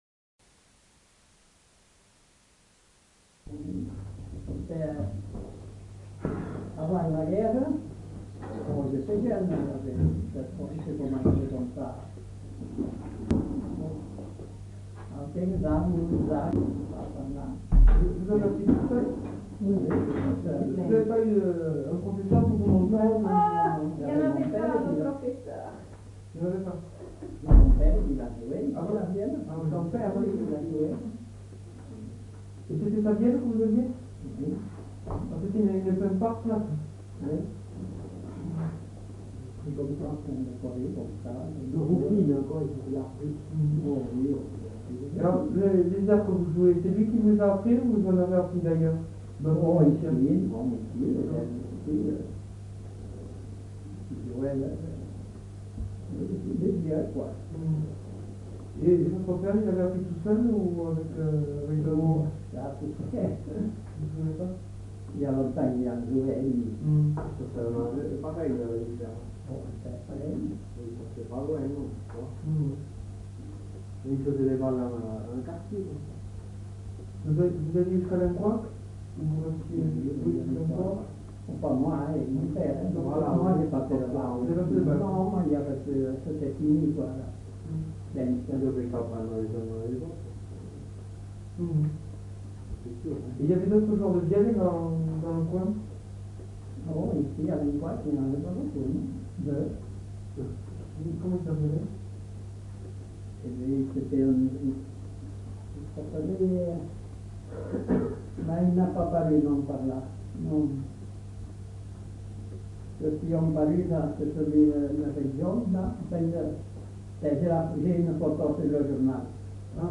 Lieu : Lencouacq
Genre : témoignage thématique